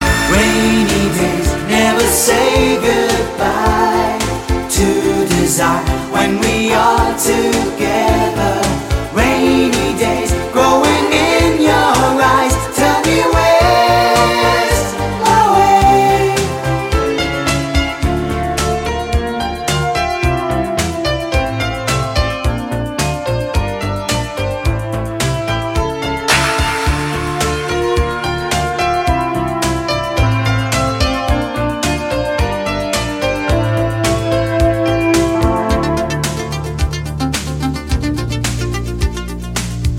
танцевальные
итало диско